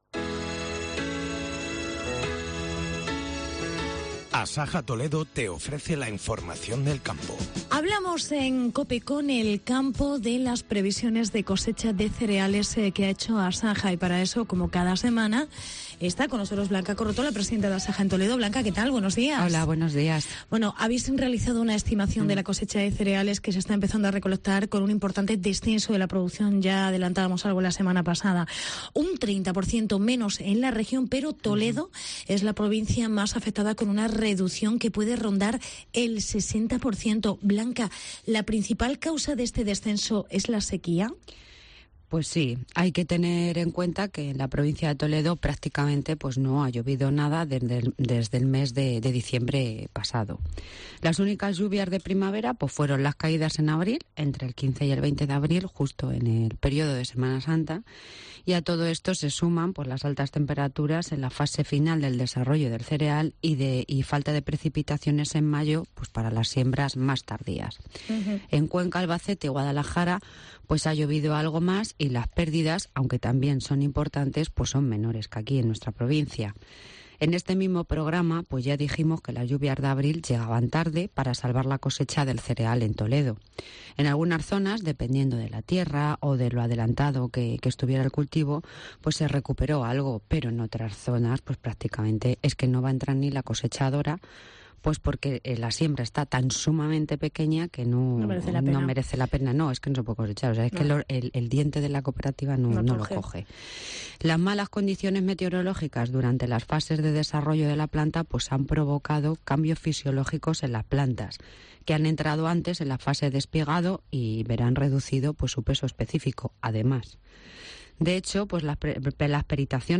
La sequia reduce en un 60% la producción de cereal en Toledo. Entrevista